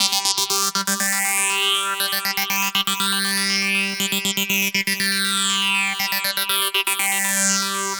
TSNRG2 Lead 002.wav